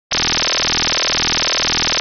IKONKA GŁOśNIKA Przykład częstotliwości podstawowej 440 Hz i modulującej 20 Hz